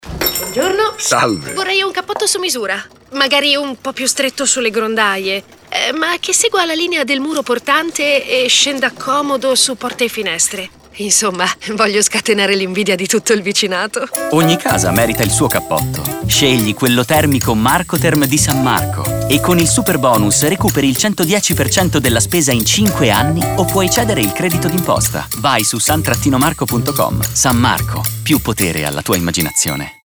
San Marco, in continuità con il claim della campagna TV “Più potere alla tua immaginazione”, torna in radio con un nuovo spot da 30’’, ideato da RedCell, rivolto ai consumatori.